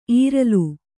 ♪ īralu